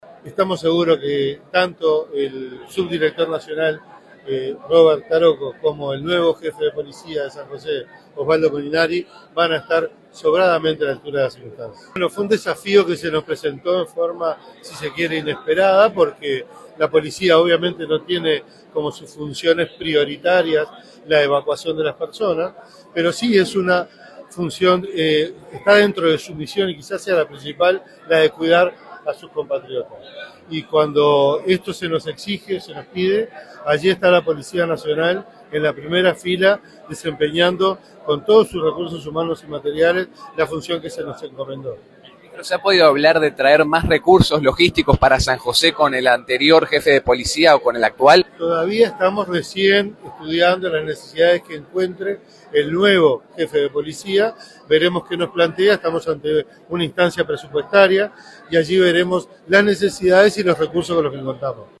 En el mismo acto, fue consultado el ministro Carlos Negro sobre la necesidad de reforzar los recursos en San José. Negro reconoció que hay carencias logísticas y de personal y aseguró que ya están trabajando en reforzar la operativa y el equipamiento, atendiendo las particularidades del departamento.
CARLOS-NEGRO-MINISTRO-DEL-INTERIOR.mp3